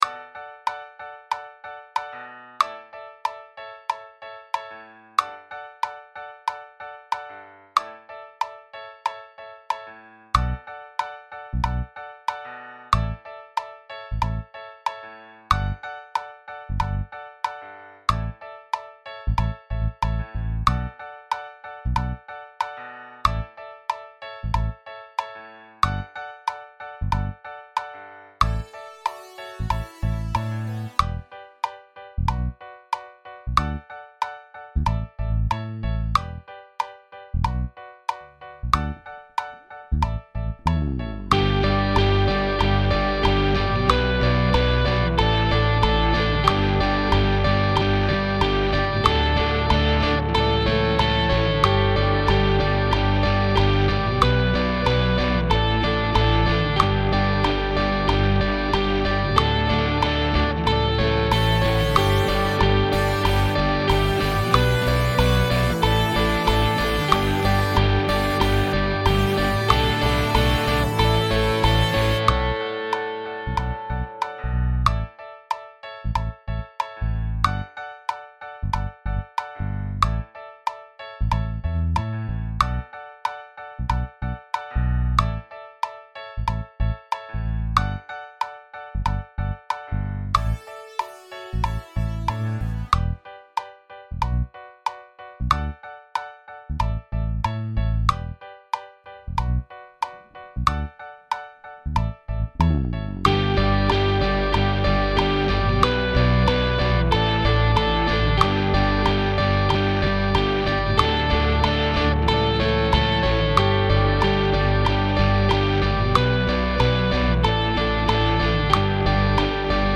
Ready or Not no drums, with click